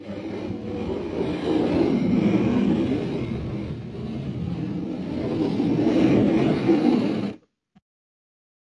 APPLE FAST fx
描述：快吃一个苹果。